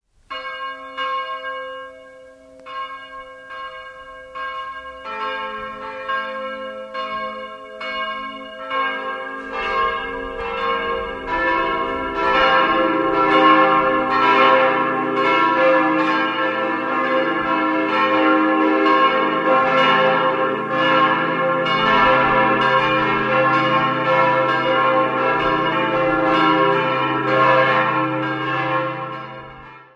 5-stimmiges Geläut: c'-e'-g'-a'-c'' Die beiden großen und die kleinste Glocken wurden 1962 von Georg Hofweber in Regensburg gegossen, die Glocken 3 und 4 1949 von Petit&Edelbrock in Gescher.